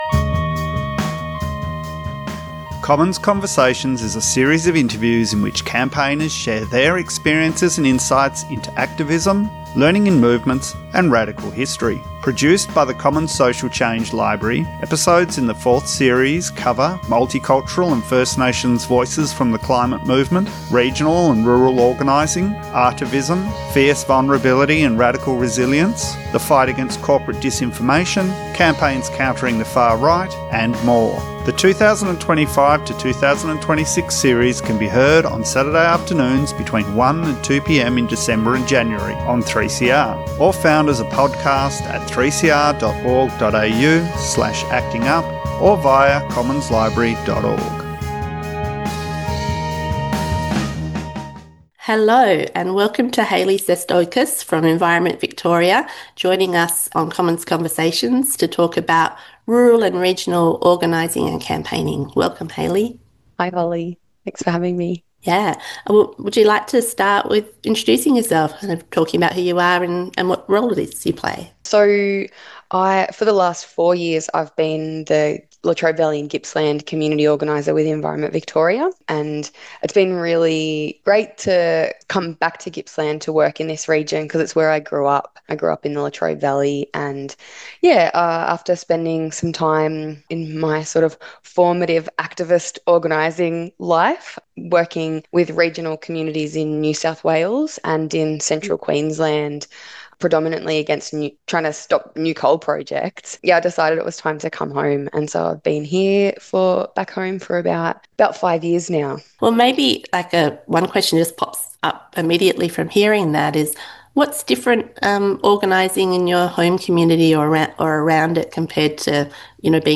This conversation will elaborate upon the relations between sovereignty and dwelling struggle in the political projects of panellists. Themes include incarceration, displacement, policing and race, making explicit connections between those intersections of dwelling and sovereignty.